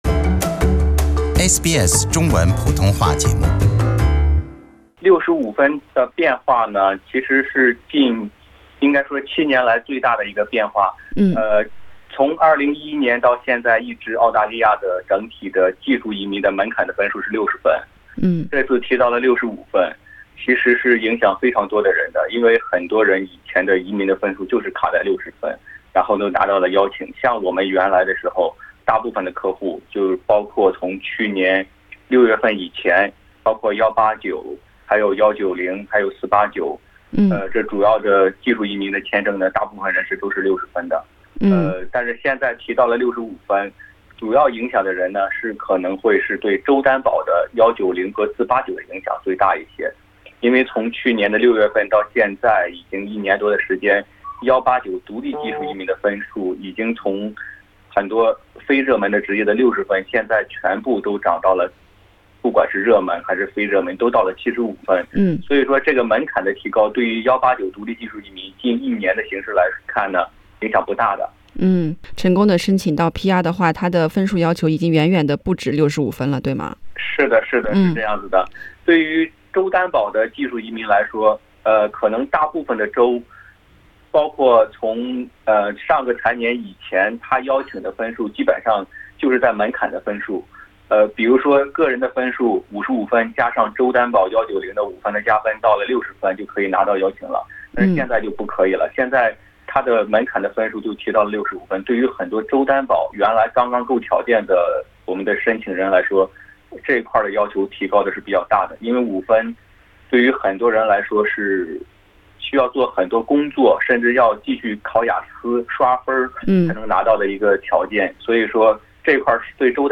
如何应对这些变化？请收听上方采访。